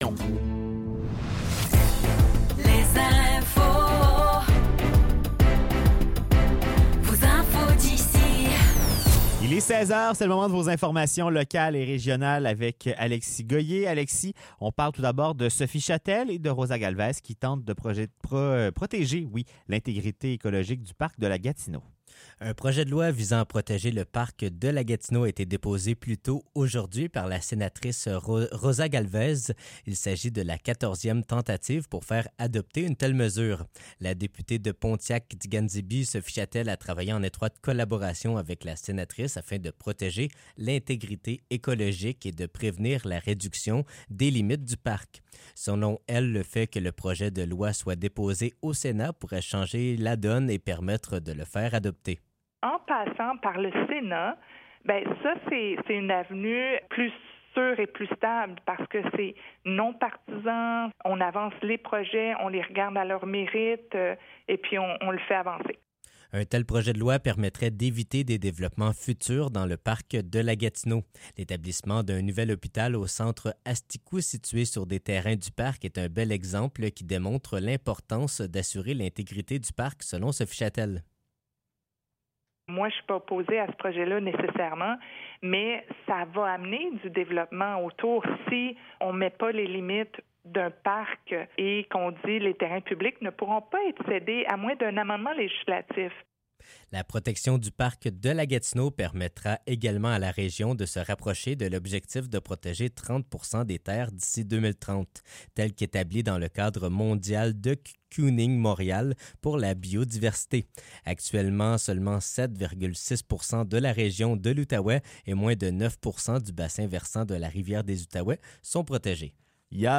Nouvelles locales - 10 octobre 2024 - 16 h